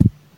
beeb kick 19
Tags: 808 drum cat kick kicks hip-hop